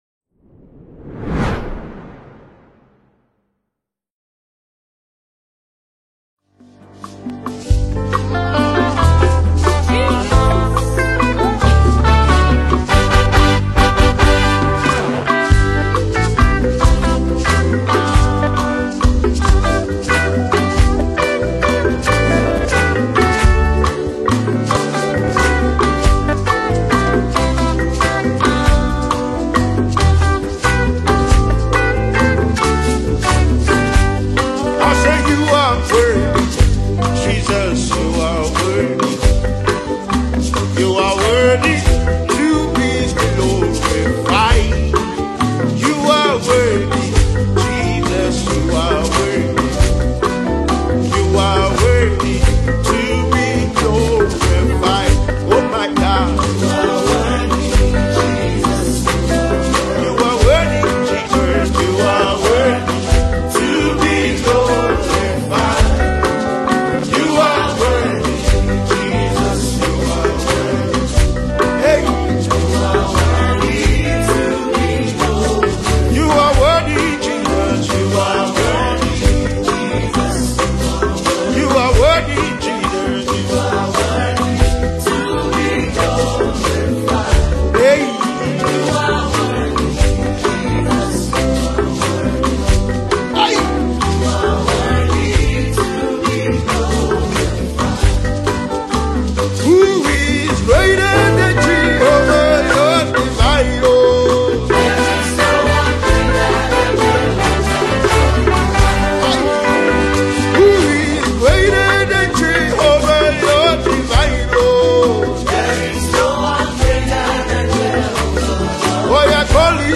Christian/Gospel